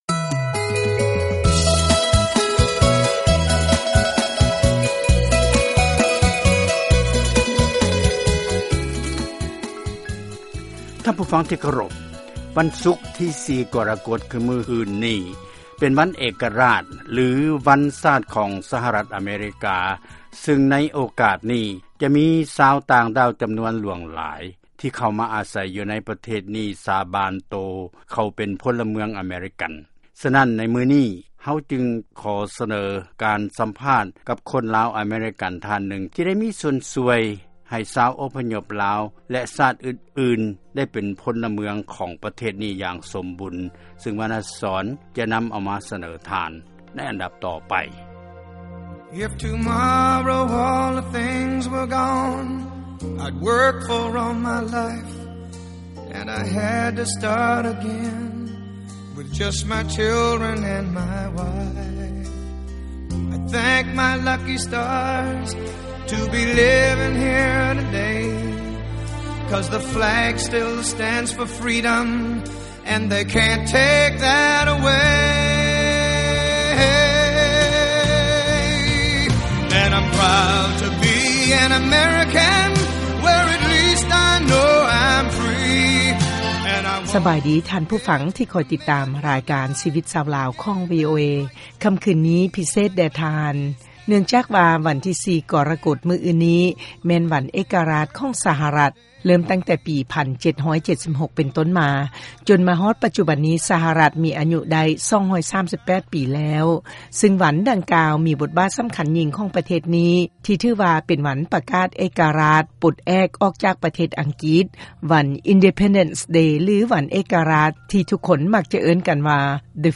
ຟັງລາຍການສຳພາດກ່ຽວກັບການຖືສັນຊາດອາເມຣິກັນ